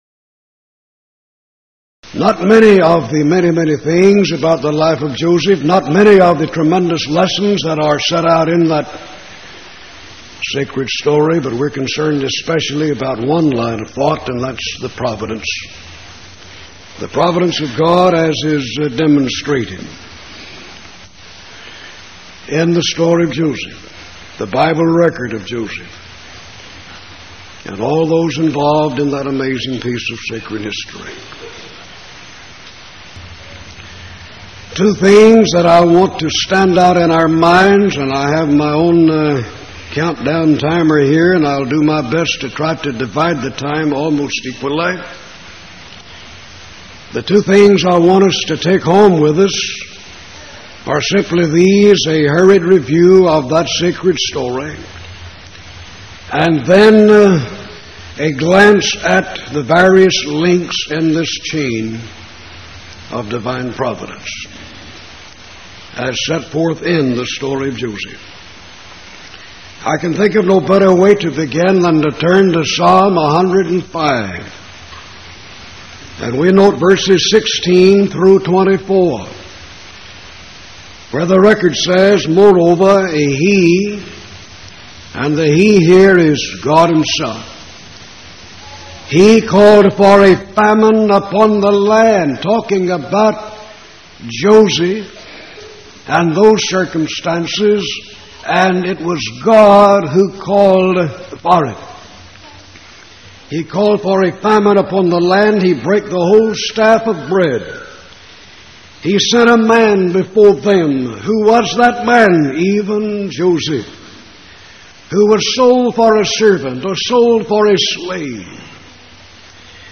Series: Power Lectures Event: 1989 Power Lectures